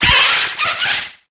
Cri de Grillepattes dans Pokémon HOME.